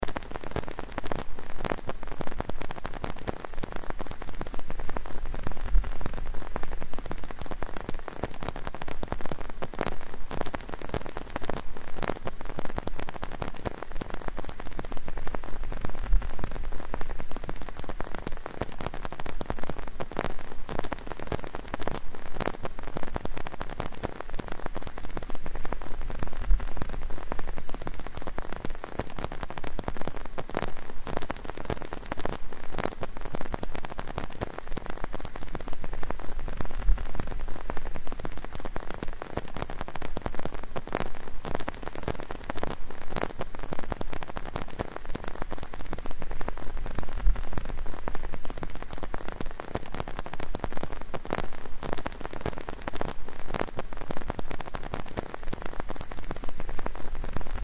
Звуки зонтика
Капли дождя отбивают ритм по зонту